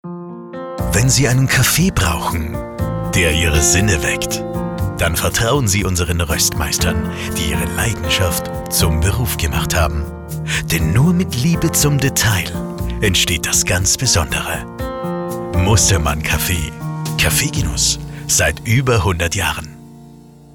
Links hört ihr die Stimme unseres Sprechers, rechts die KI-generierte Variante – ein direkter Vergleich für Ausdruck, Natürlichkeit und Emotionalität.
Radiowerbespot 01
Radiospot "Kaffee"